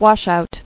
[wash·out]